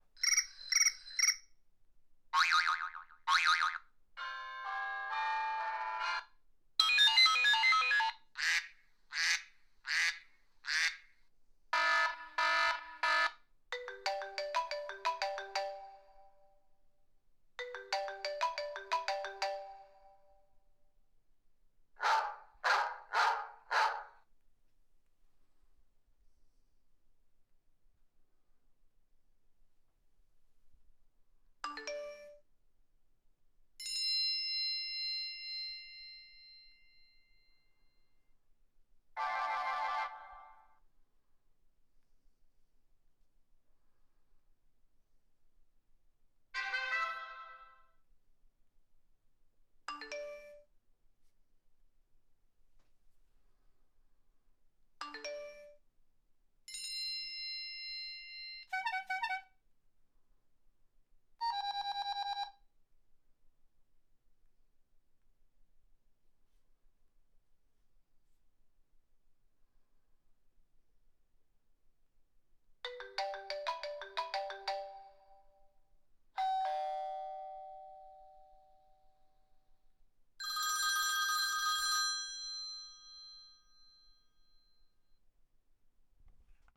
Sound Effect
Phone Alerts And Rings
Phone_Alerts_And_Rings.mp3